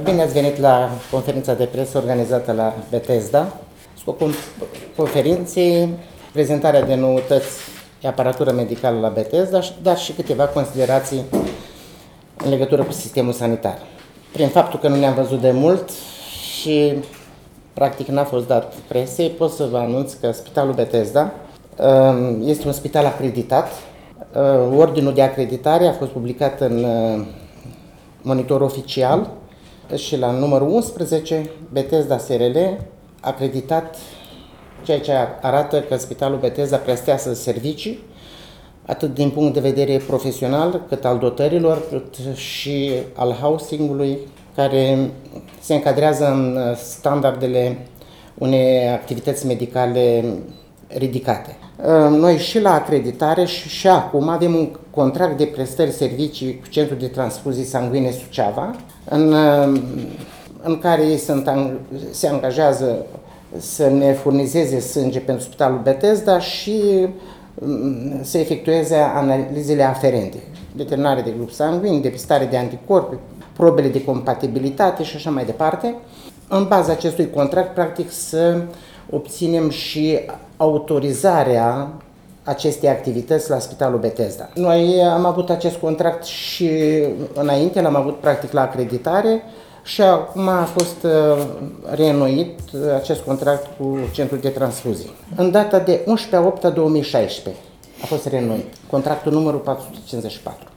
Spitalul Bethesda din Suceava – conferinţă de presă